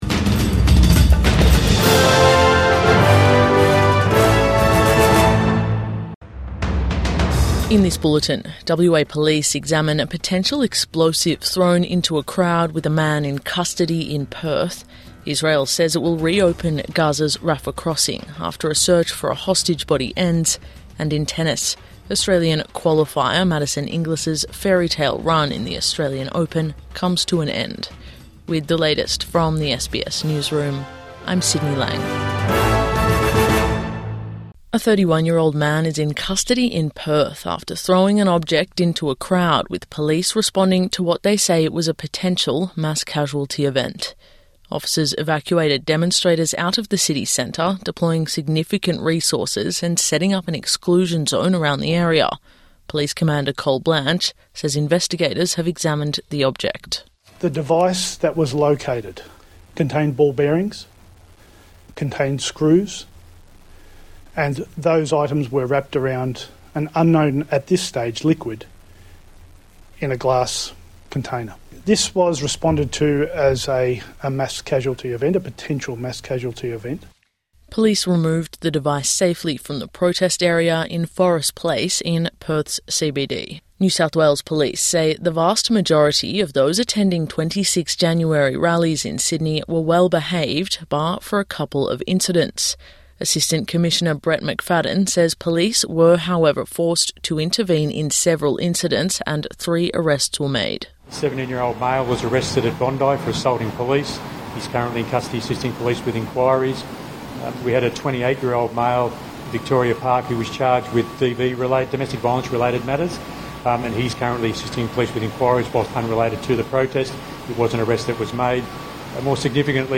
Potential mass casualty event averted in Perth | Morning News Bulletin 27 January 2026